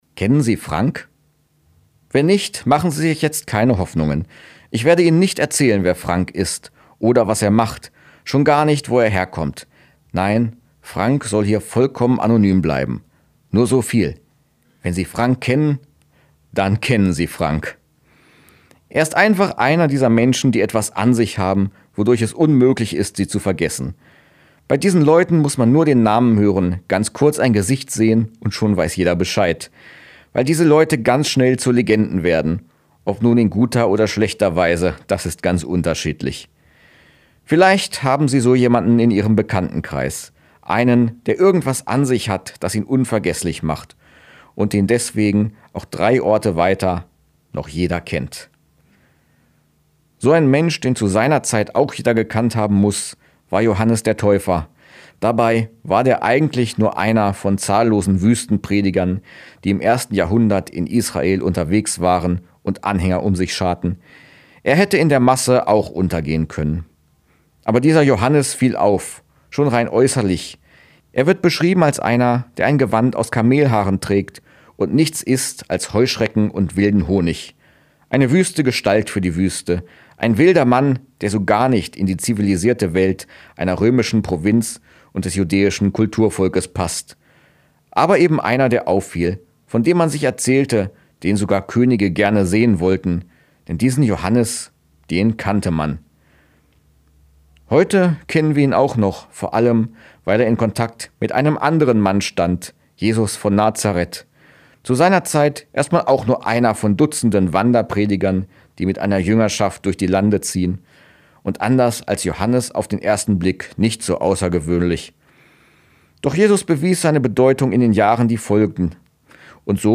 Radioandacht vom 13. September